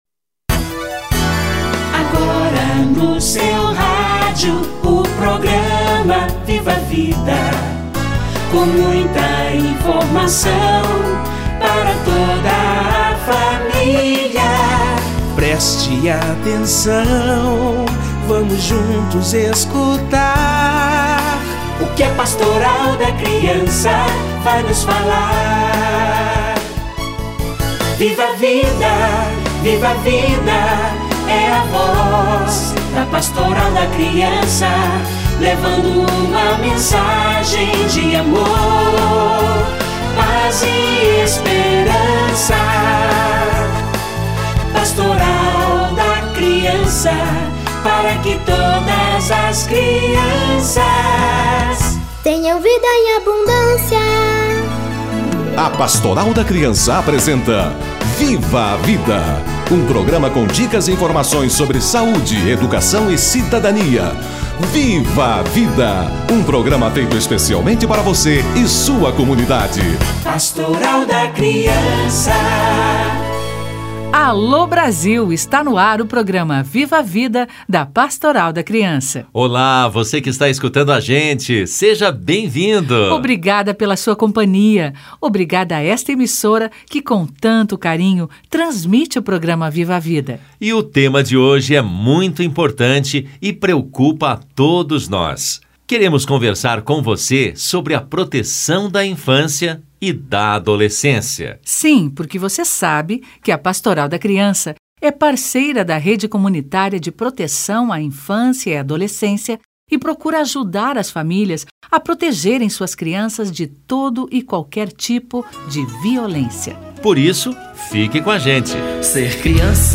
Rede Comunitária de proteção à criança - Entrevista